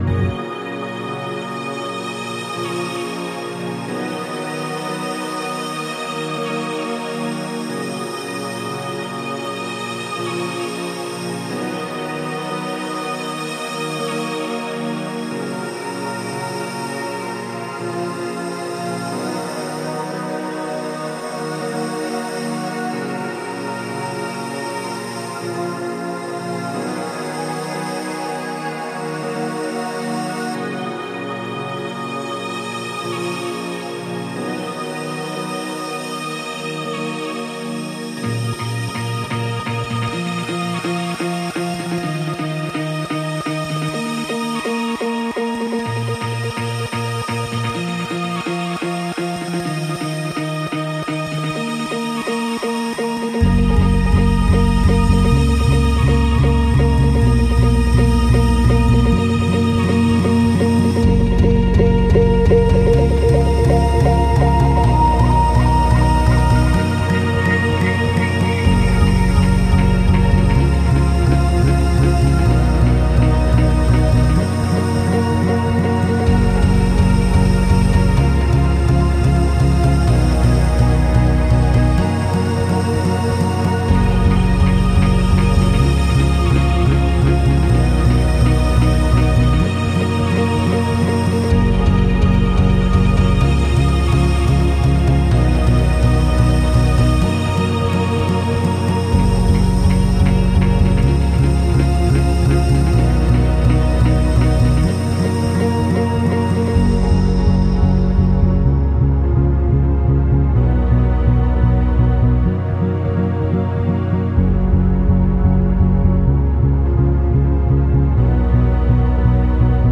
Techno , Trance